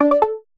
notification_sounds
tintong.ogg